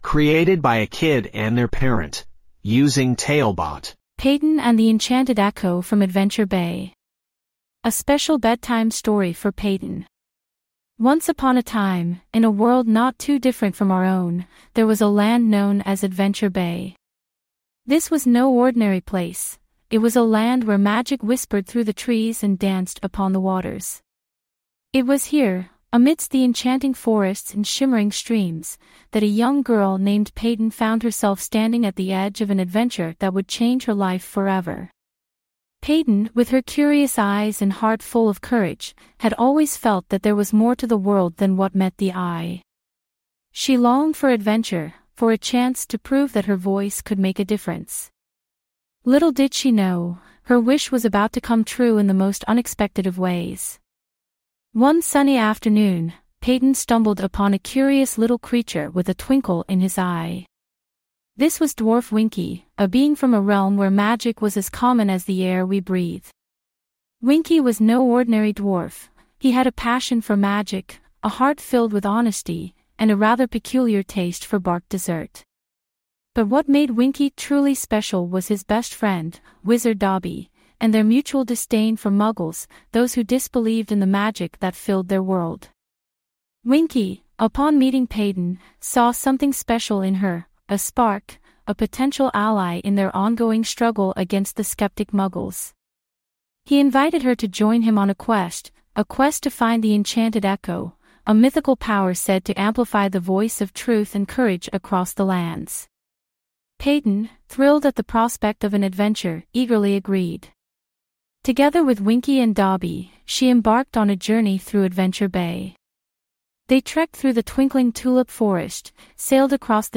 5 minute bedtime stories.
TaleBot AI Storyteller